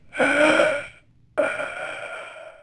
monster.wav